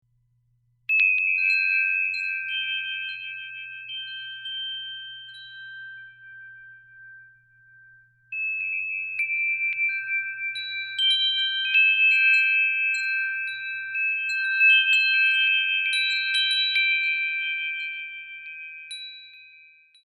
Woodstock Craftsman Wind Chime - Mission Courtyard
The Woodstock Craftsman Chime - Mission Courtyard repeats patterns of circles and pentagons and is precision-tuned according to the Golden Ratio, a design tool often used in Mission style architecture.
teak finish Ash wood, 6 square bronze tubes.